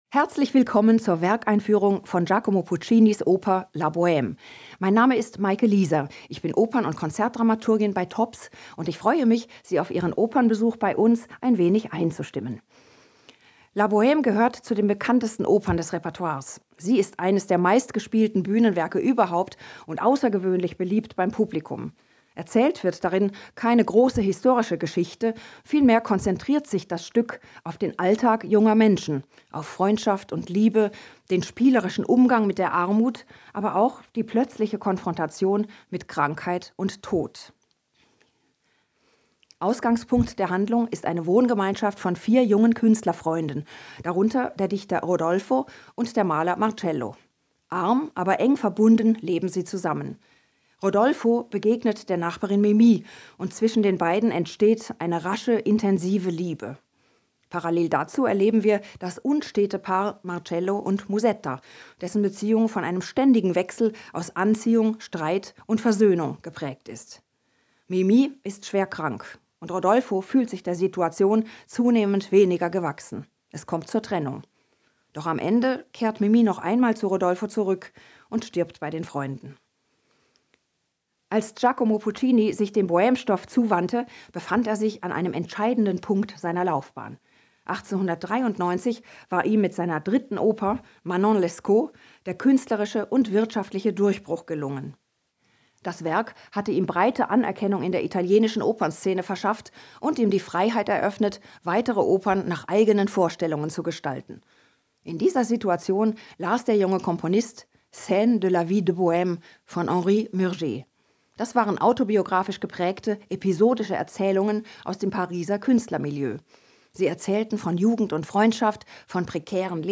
Pause) INFO Talk im Theater um 18:45 mit taktilen Elementen AD Mit Audiodeskription für blinde und sehbehinderte Menschen ALTERSEMPFELUNG 12+ AUDIO-WERKEINFÜHRUNG hier AUDIODESKRIPTION hier PROGRAMMHEFT hier
La_Boheme_Einfuehrung_D.mp3